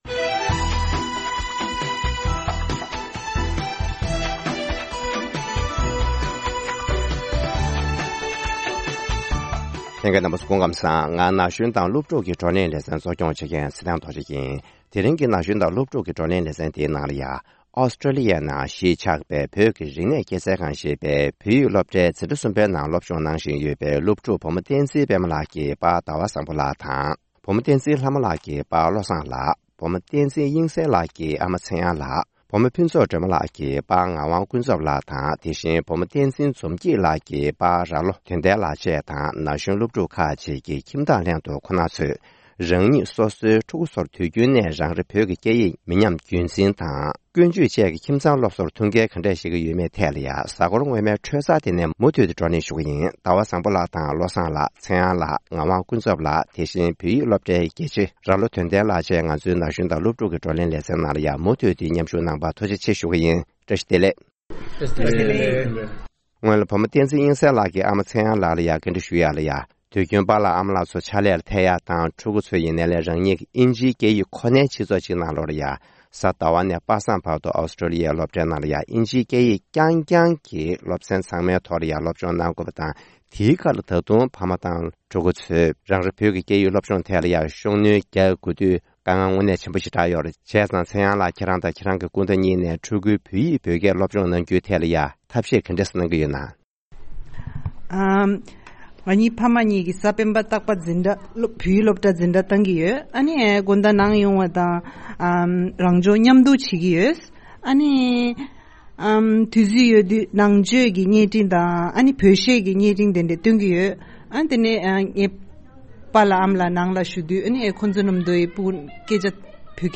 ན་གཞོན་དང་སློབ་ཕྲུག་གི་བགྲོ་གླེང་ལེ་ཚན་འདིའི་ནང་དུ།